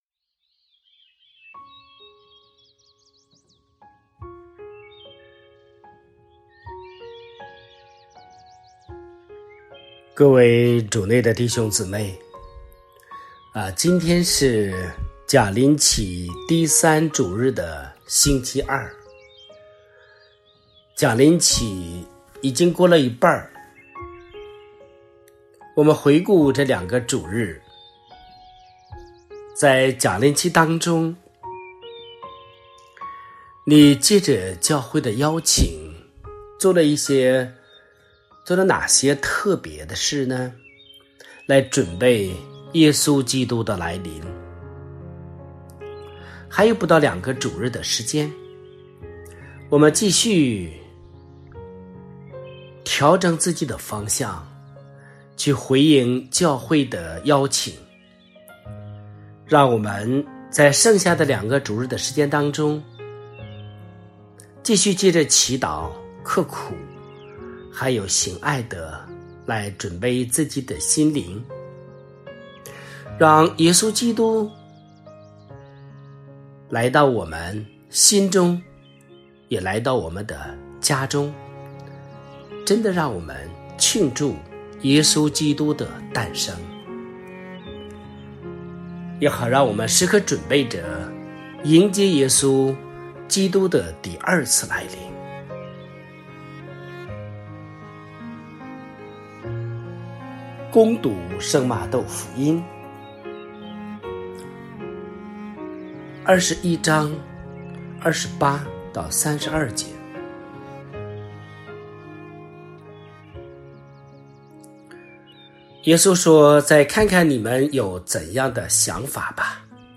这是第四个月听神父讲道了